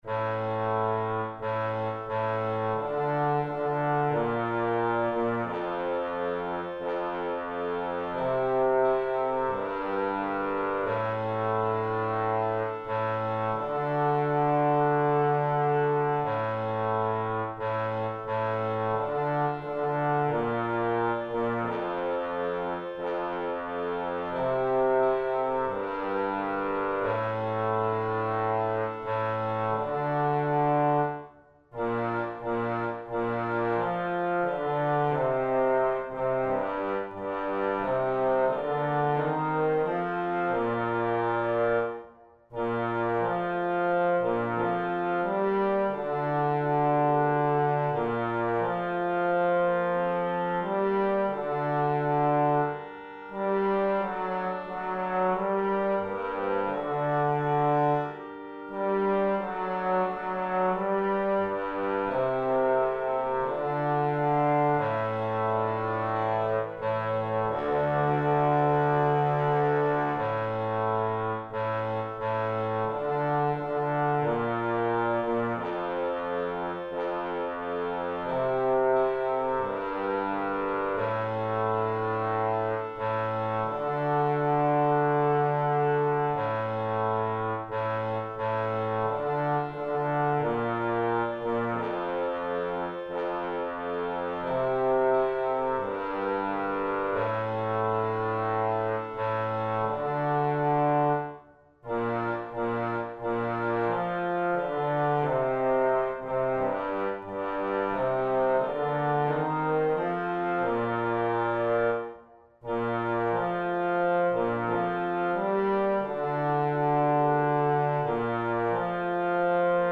Bass2
Anthem